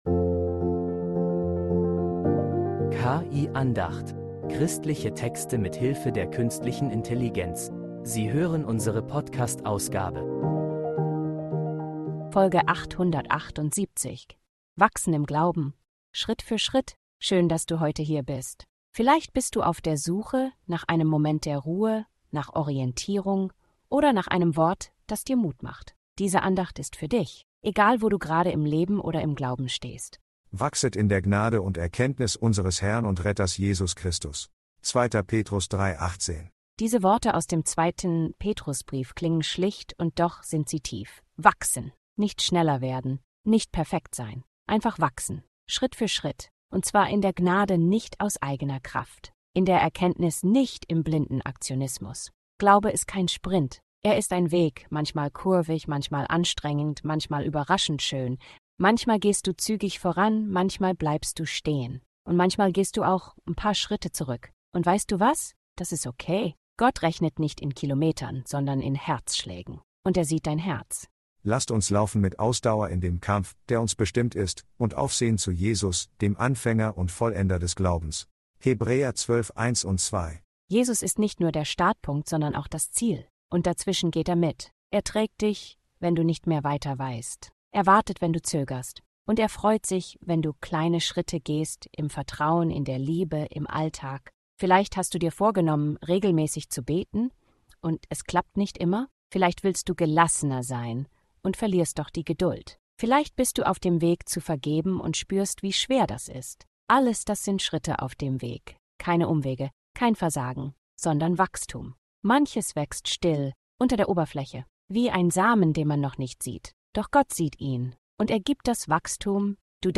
KI-Andacht